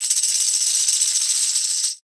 Index of /90_sSampleCDs/Best Service ProSamples vol.42 - Session Instruments [AIFF, EXS24, HALion, WAV] 1CD/PS-42 AIFF Session Instruments/Percussion